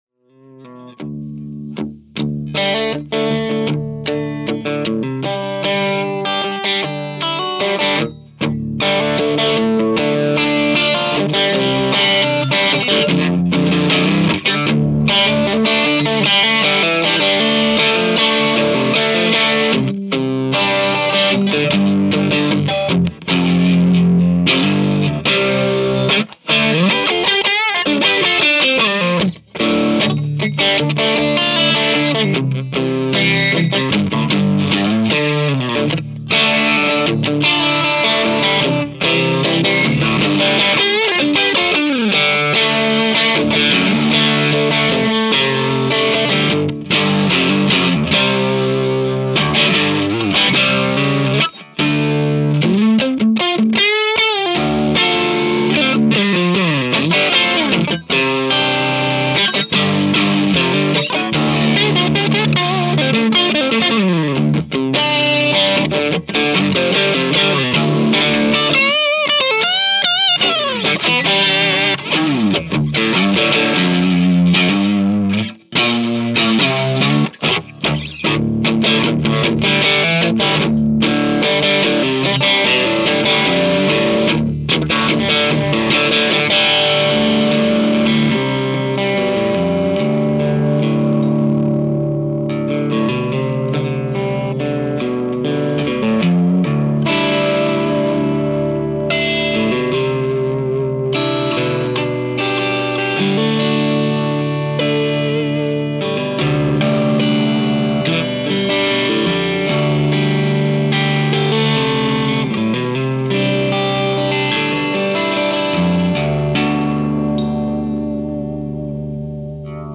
This is my sIII with the boost on, TMB channel only. The guitar is a strat.
Trinity 2x12 with 2 tone tubby ceramics
Mike - SM-57 About 18 inches from cab
Guitar - 62ri Strat
Boost On
Strat_sIII_BoostOn.mp3